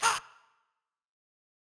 MB Vox (13).wav